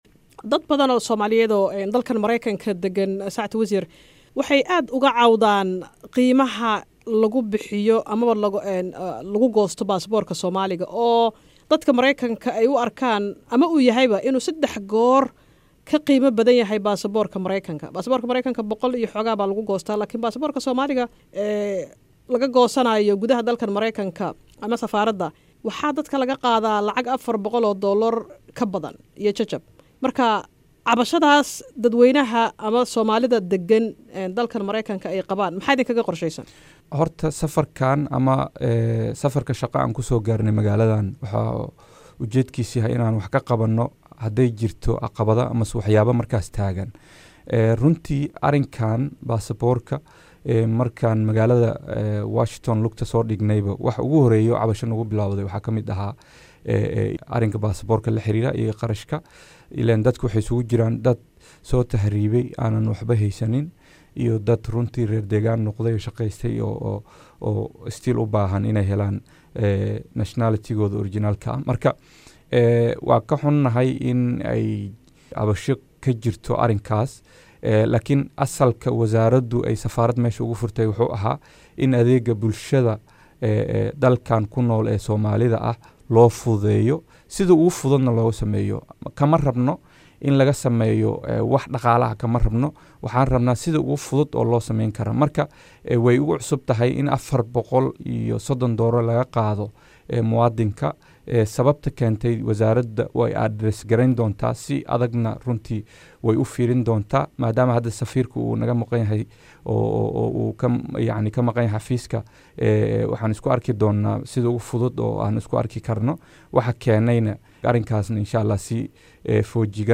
Wasiir ku xigeenka oo wareysi siiyey VOA ayaa sheegay in arrintaas ay si deg deg ah wax uga qaba doonaan, isagoo cadeeyey in wasaaraddu aysan waxaba ka ogeyn qiimahaan badan.